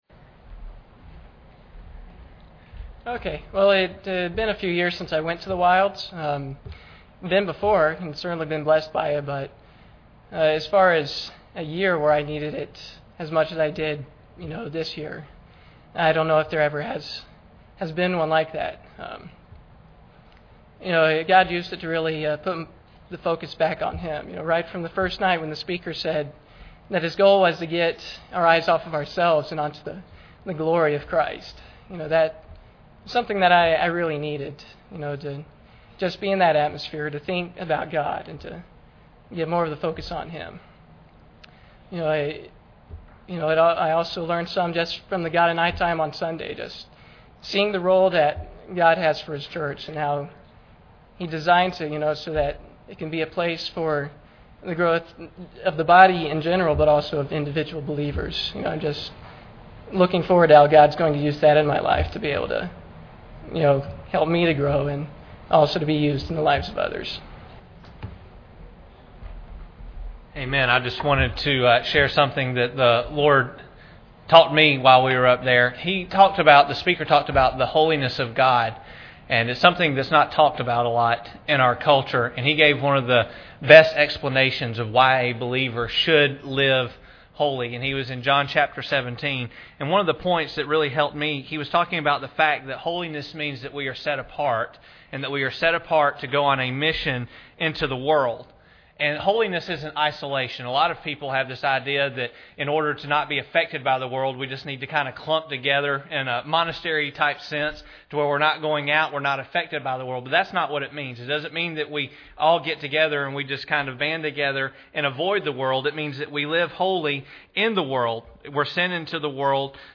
Testimonies shared by the Life Purpose and Youth Groups on their respective trips to the Wilds.
Service Type: Sunday Evening